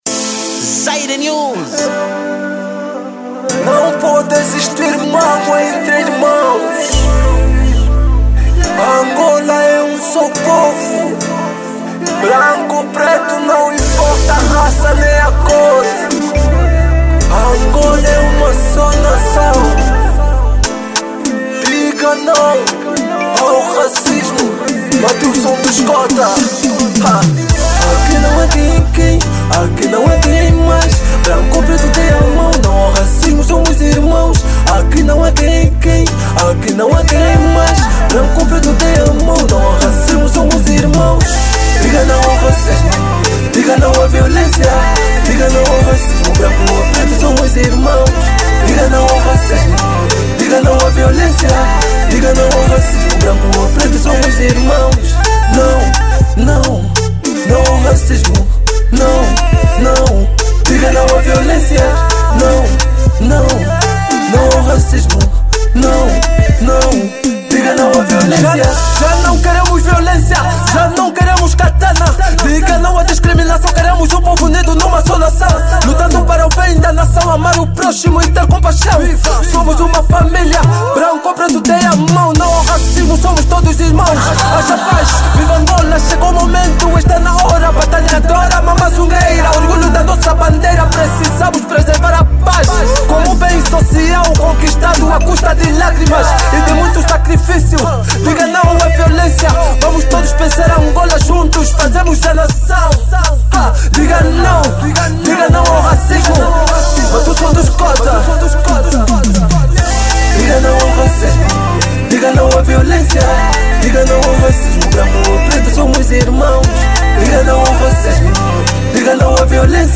Gênero:Kuduro